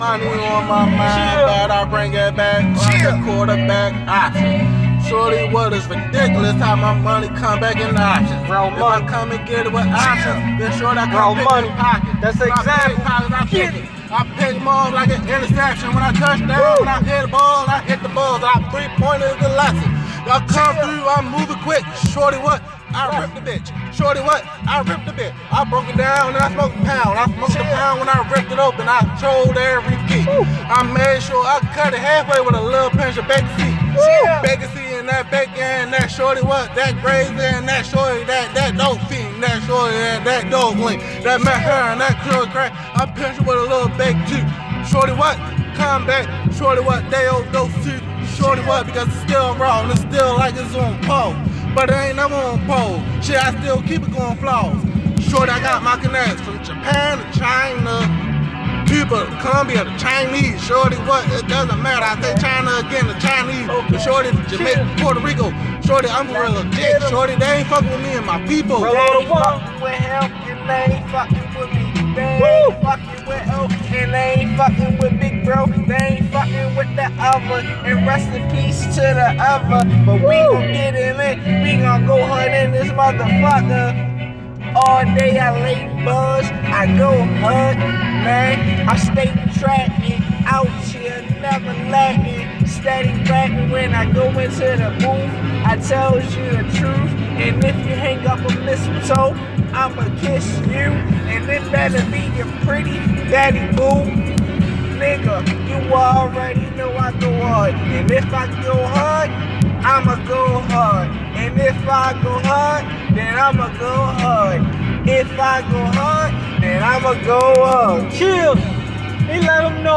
It's Freestyled